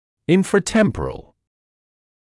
[ˌɪnfrə’tempərəl][ˌинфрэ’тэмрэрэл]нижне-височный